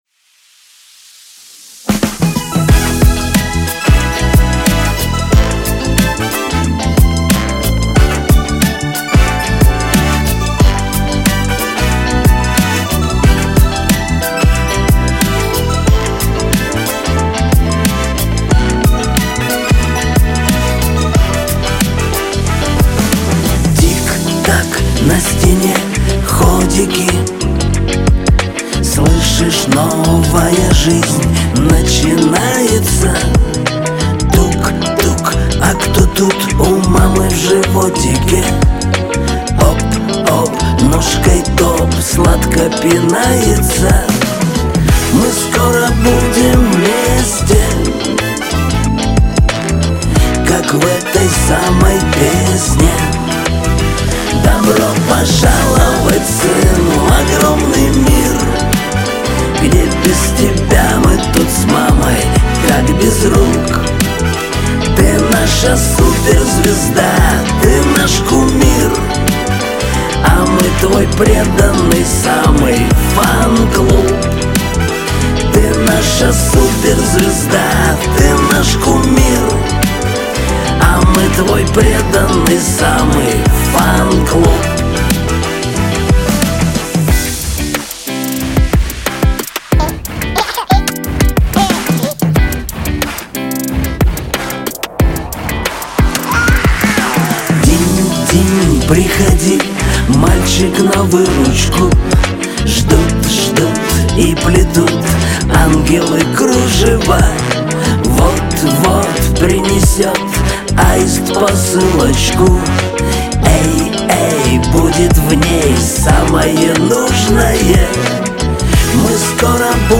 Качество: 320 kbps, stereo
Поп музыка